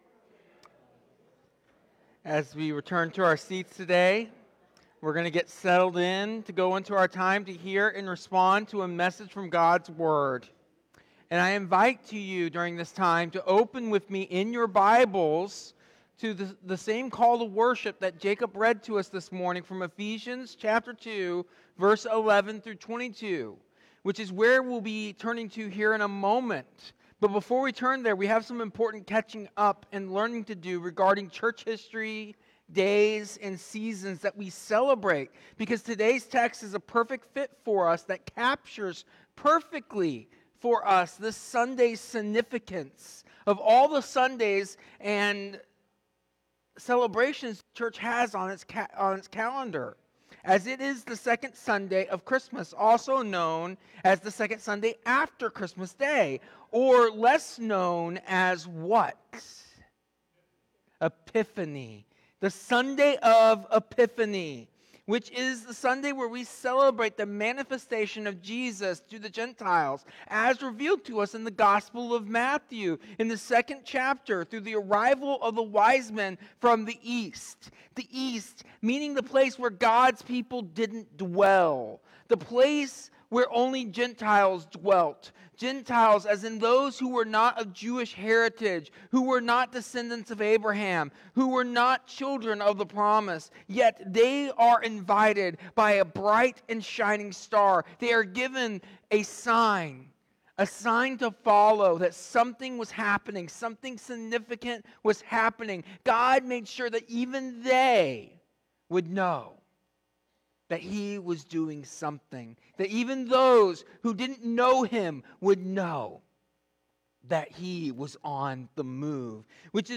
This sermon centers on Epiphany, which is the celebration of Christ revealed not only to Israel but to the Gentiles.